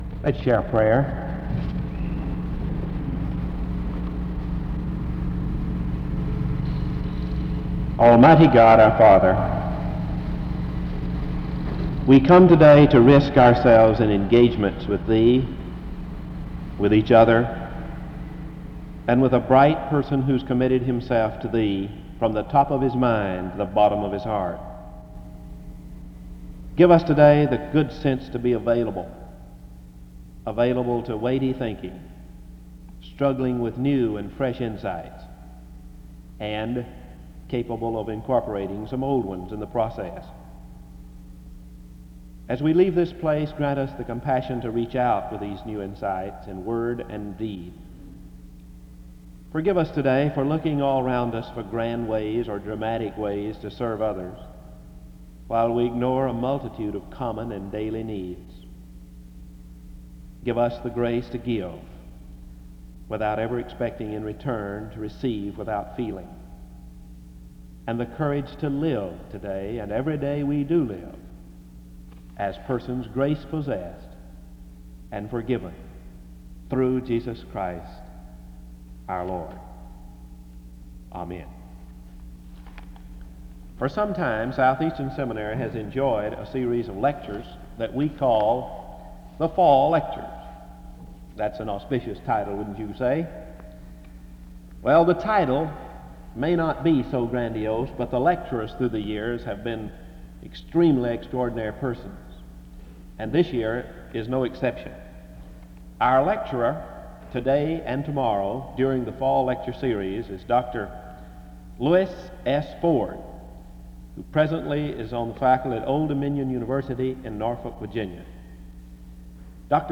SEBTS Fall Lecture